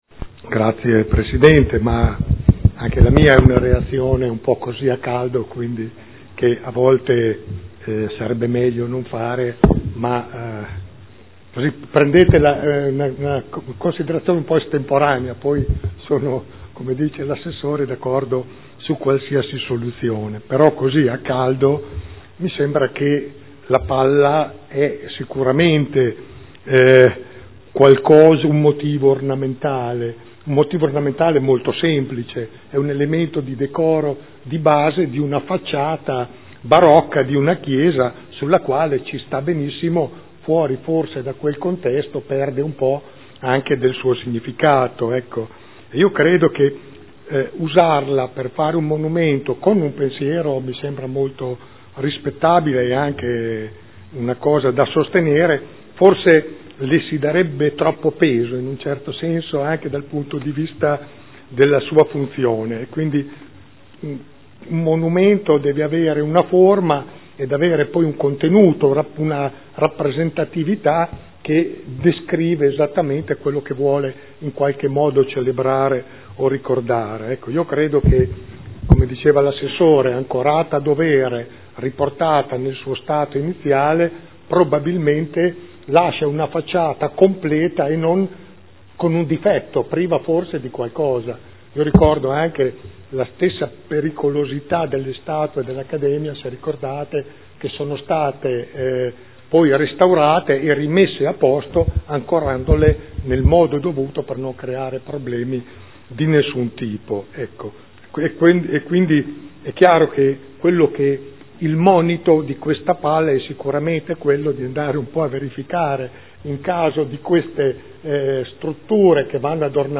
Giancarlo Pellacani — Sito Audio Consiglio Comunale